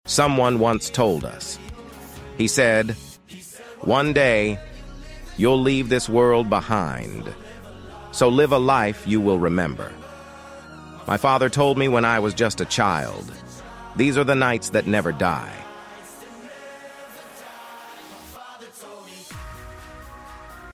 an upbeat and inspirational song